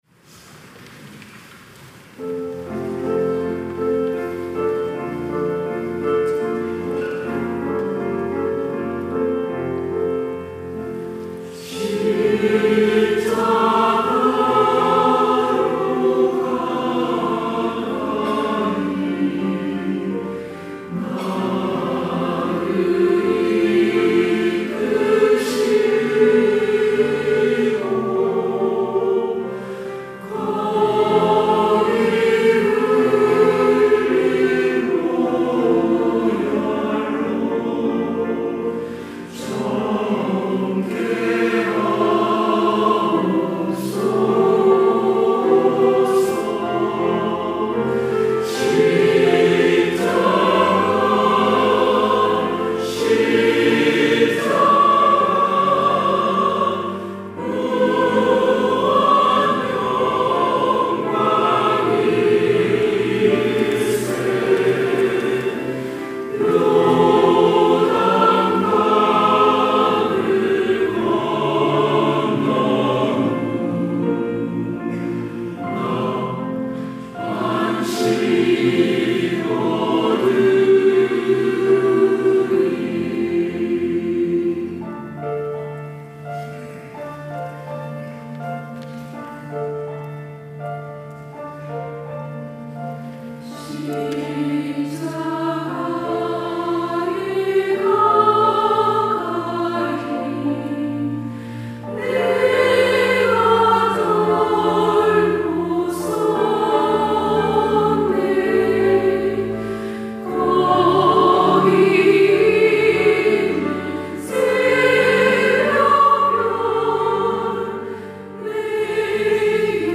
시온(주일1부) - 십자가로 가까이
찬양대